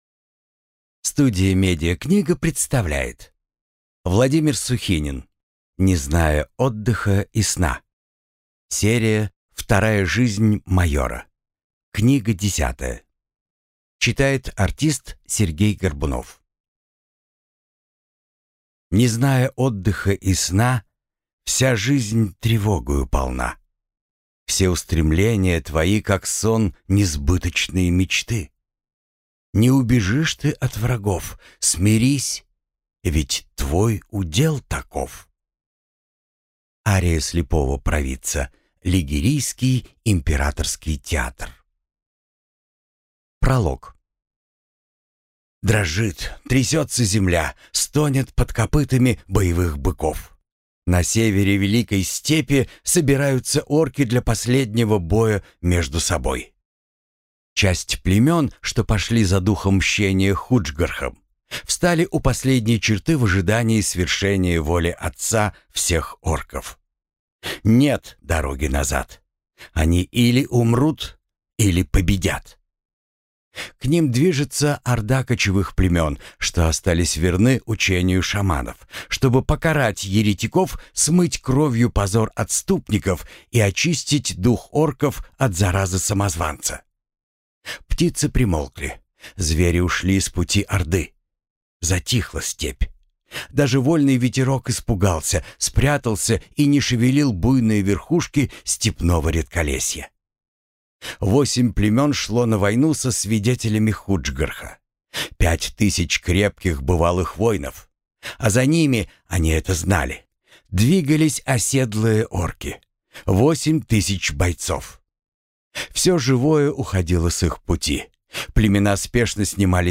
Аудиокнига Не зная отдыха и сна | Библиотека аудиокниг